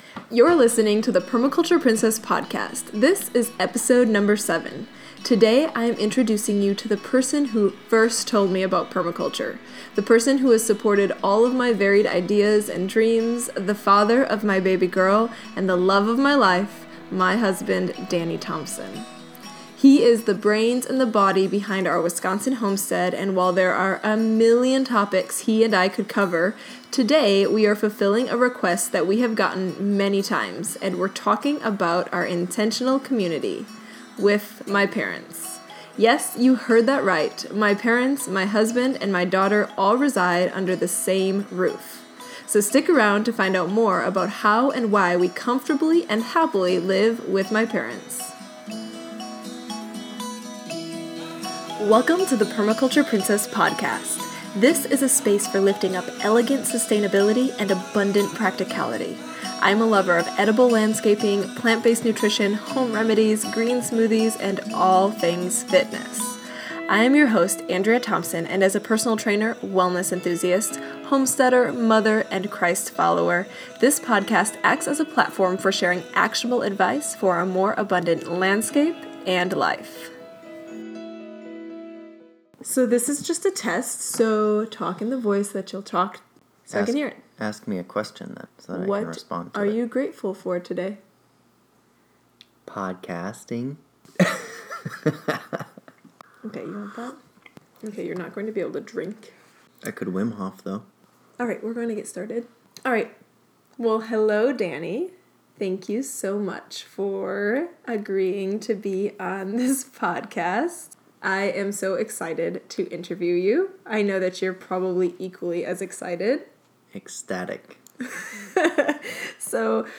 He shares some of the fears that one has in moving in with their in-laws, and some of the benefits he has found in the process. Listen in to a candid conversation (our first of more to come!) about our generational living set-up.